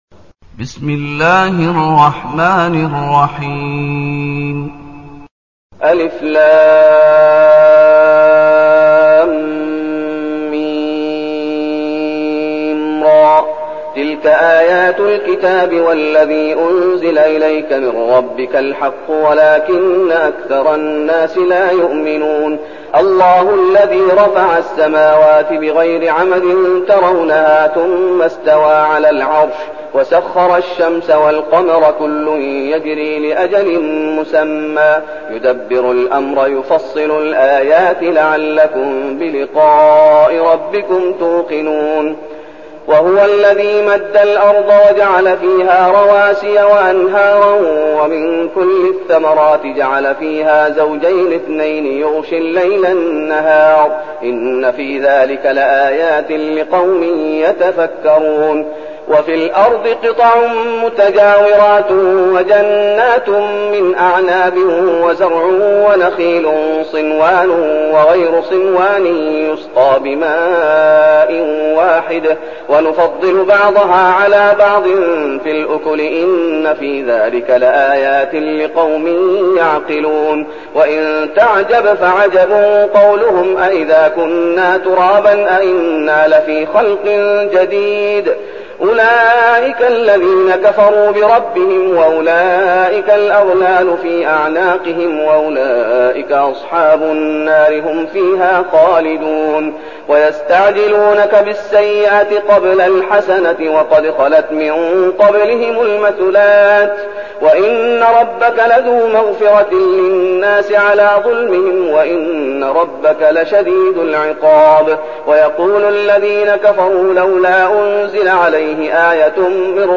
تلاوة سورة الرعد بصوت القارئ الشيخ محمد أيوب
تاريخ النشر ١ محرم ١٤١٠ المكان: المسجد النبوي الشيخ: فضيلة الشيخ محمد أيوب فضيلة الشيخ محمد أيوب سورة الرعد